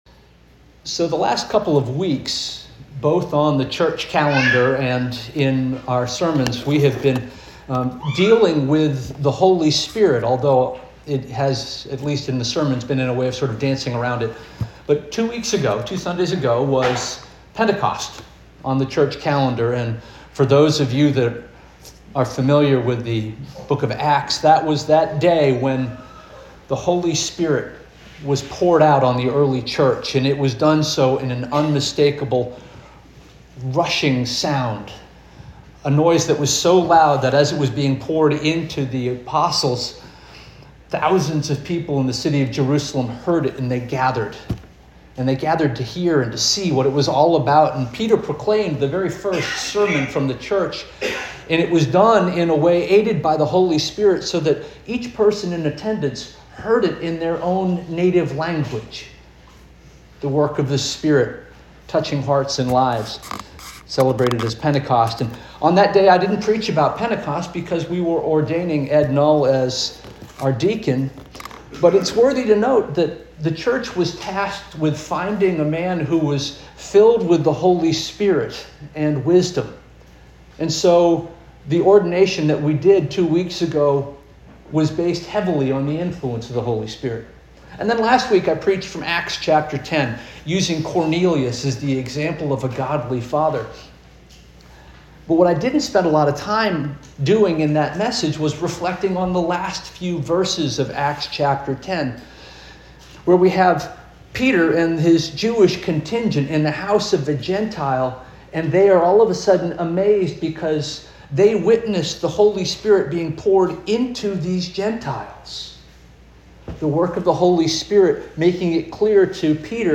June 22 2025 Sermon - First Union African Baptist Church